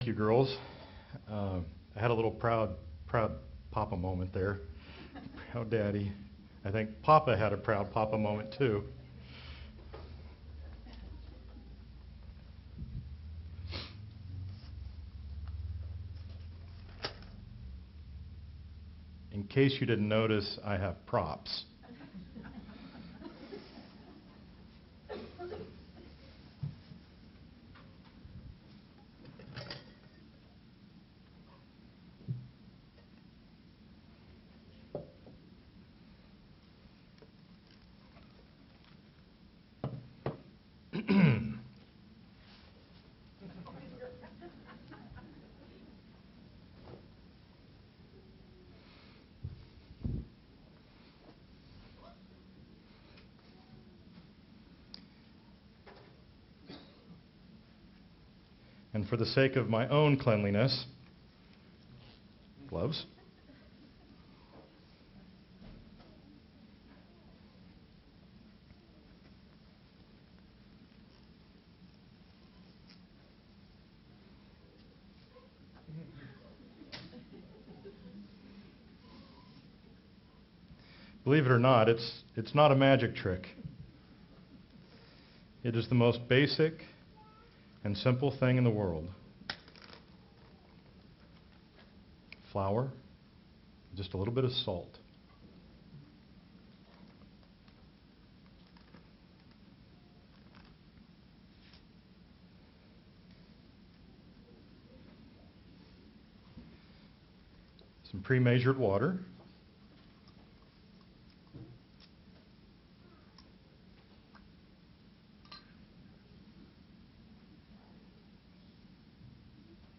Sermons
Given in Sioux Falls, SD Watertown, SD